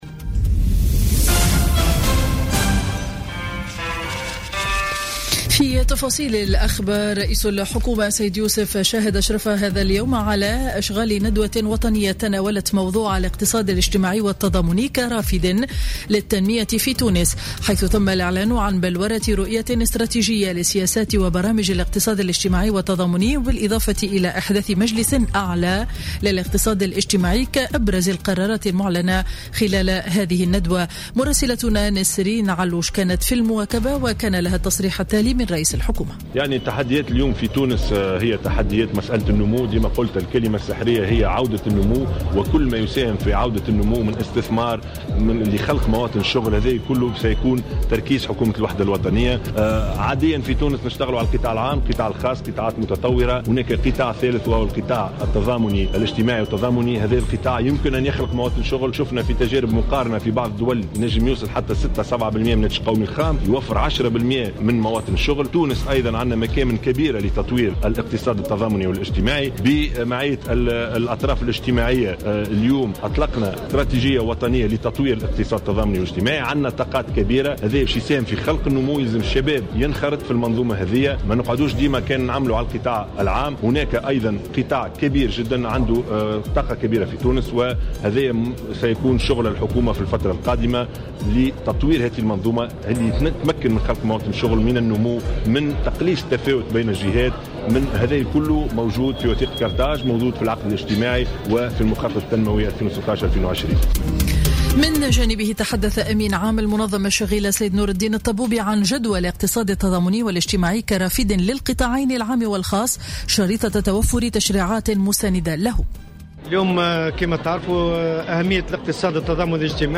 نشرة أخبار منتصف النهار ليوم الإربعاء 5 جويلية 2017